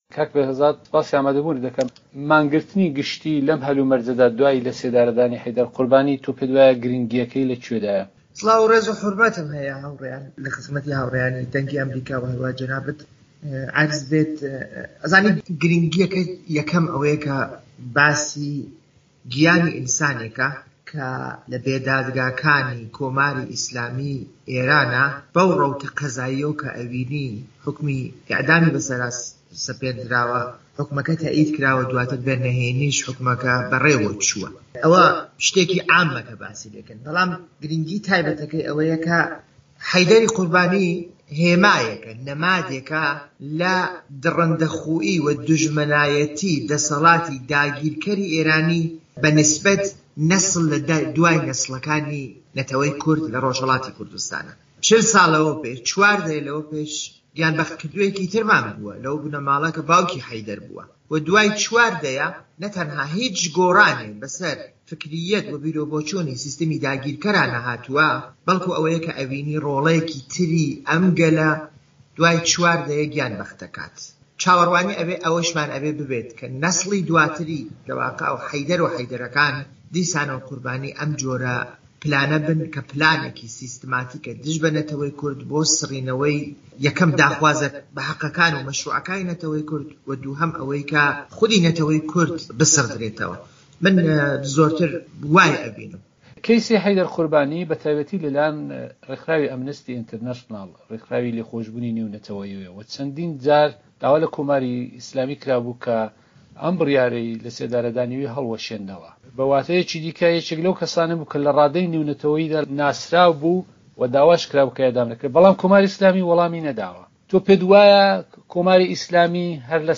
وتووێژی تۆژەری سیاسی